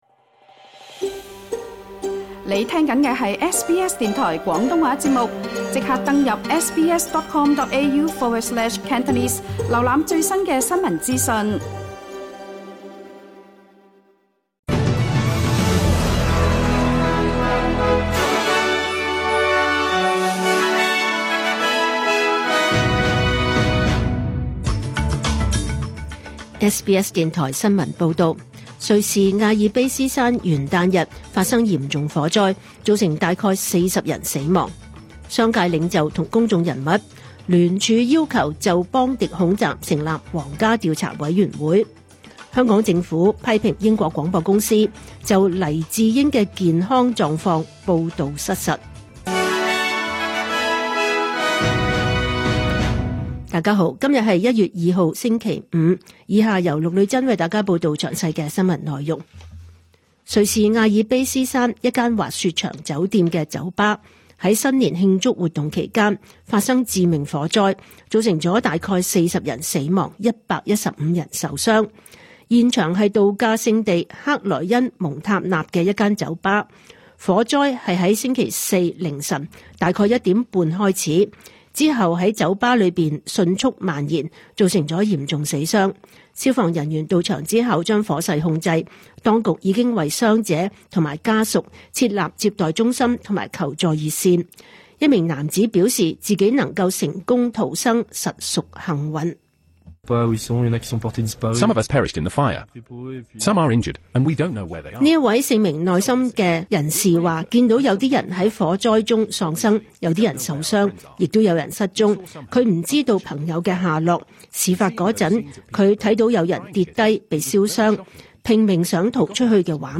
2026 年 1 月 2 日 SBS 廣東話節目詳盡早晨新聞報道。